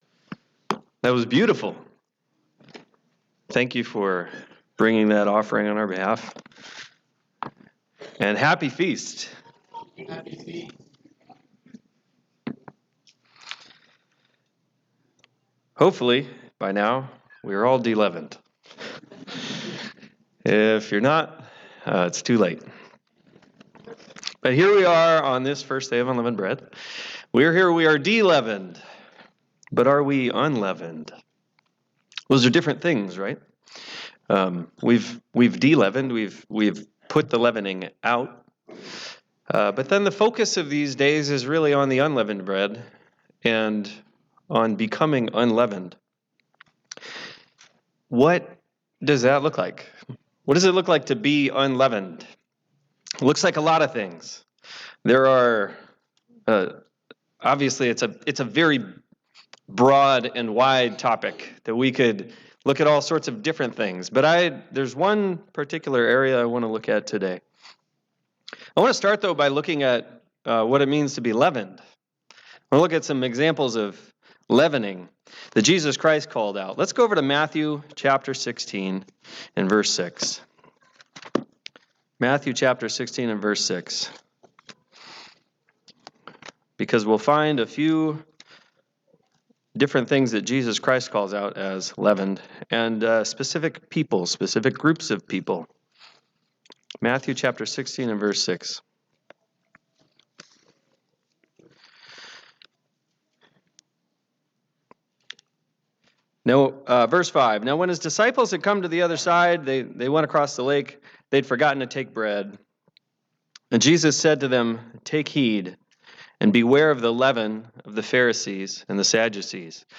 This sermon examines the unleavened mindset and the actions that mindset produces. We'll look at counter-examples before taking a deeper dive into Romans 12.